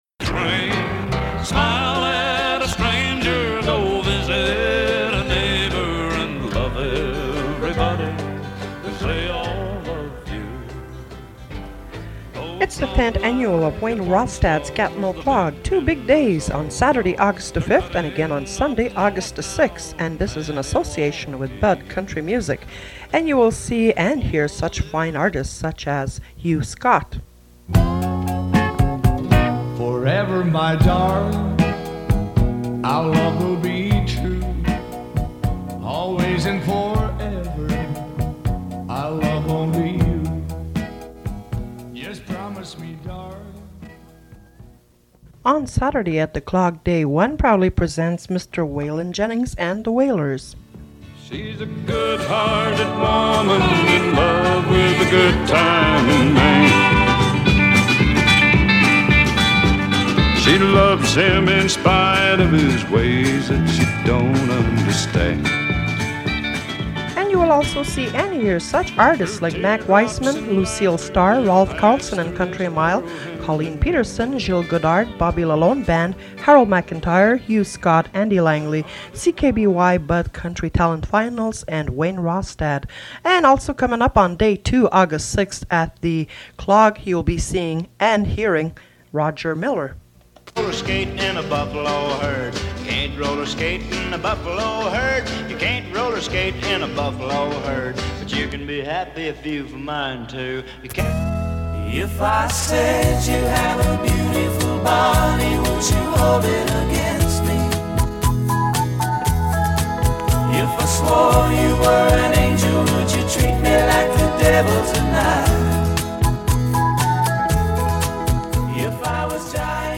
Fait partie de The ad for 10th annual Gatineau Clog Festival